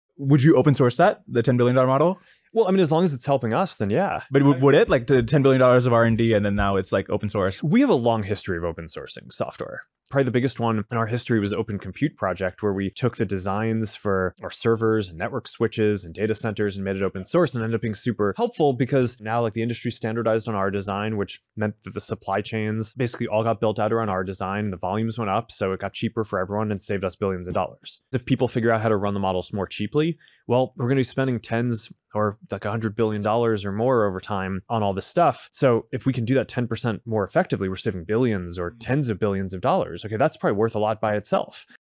interview.wav